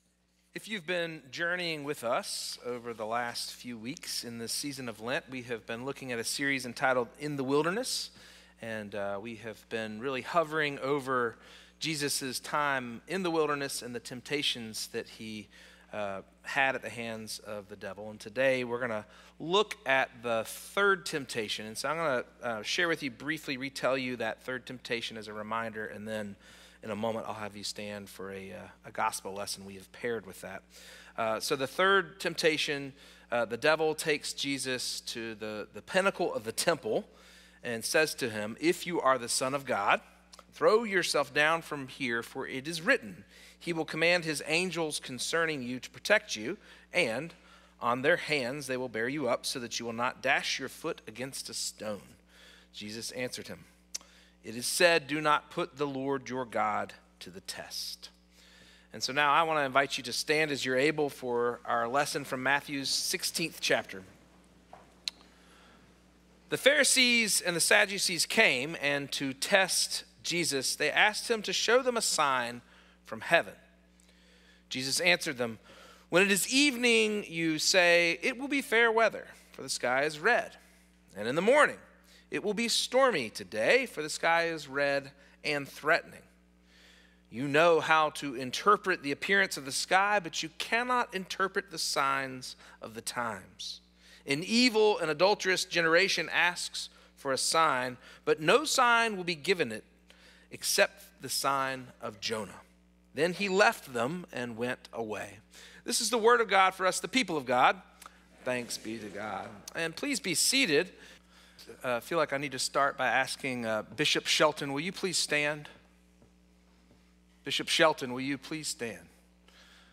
First Cary UMC's First Sanctuary Sermon